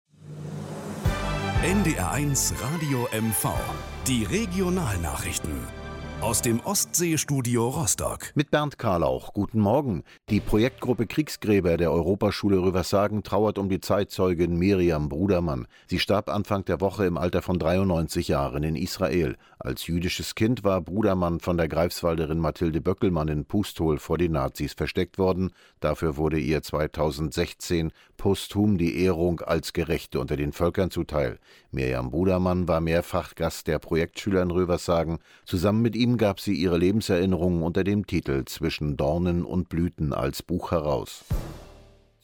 NDR-Beitrag